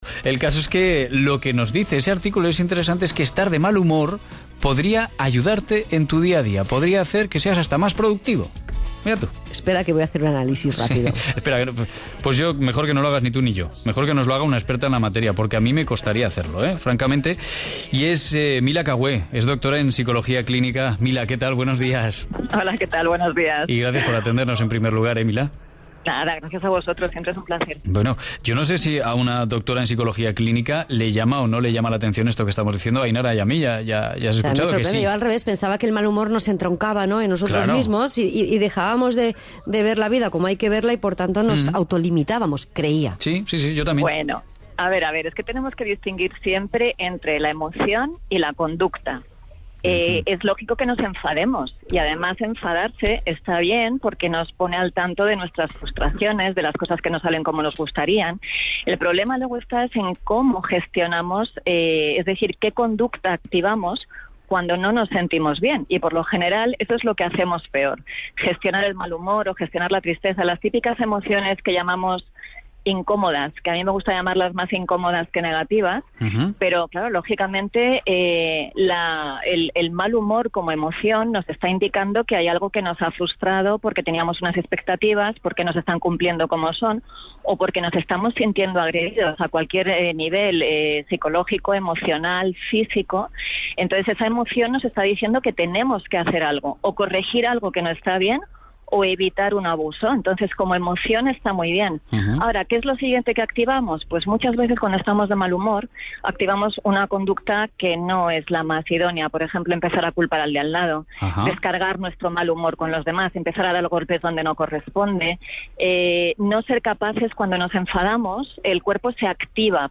Podcast: «El mal humor nos hace ser más productivos», para Aquí la Radio, Aragón Radio, 14 Agosto 2018